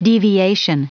Prononciation du mot deviation en anglais (fichier audio)
Prononciation du mot : deviation